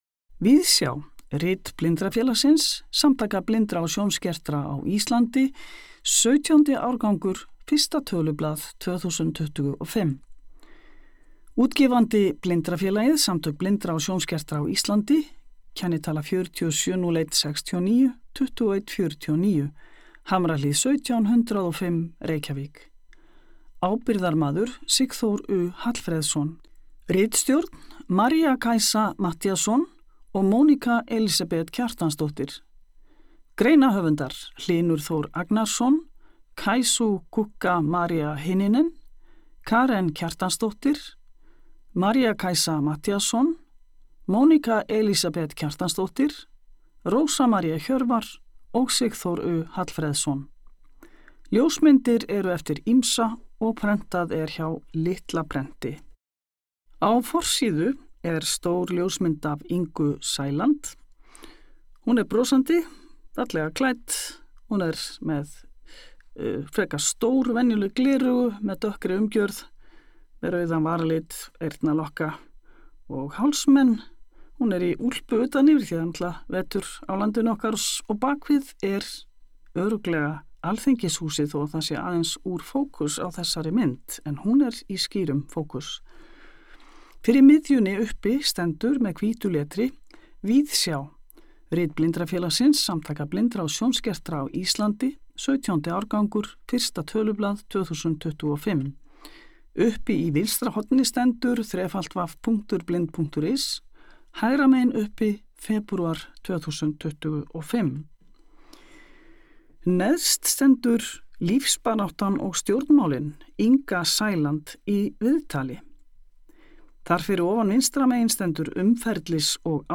Ath: Auglýsingar eru lesnar á eftir hverri grein en heilsíðuauglýsingar eru merktar í sér hljóðskrám.